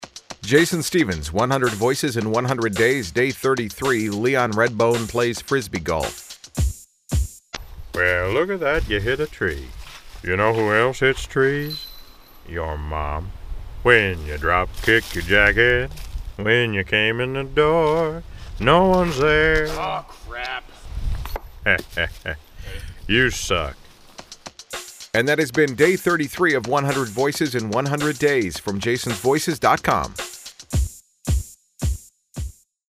This is another example of someone suggesting I do an impersonation – so, I worked up a Leon Redbone impression, today’s special.
Tags: celebrity voice overs, Leon Redbone impression, voice matching